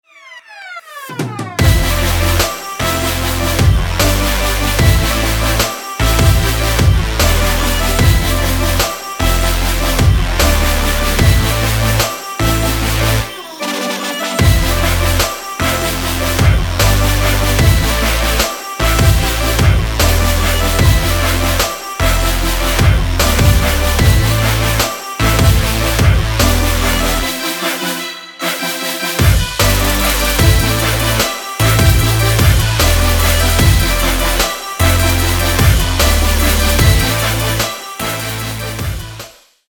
Electronic
Trap
club